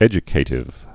(ĕjə-kātĭv)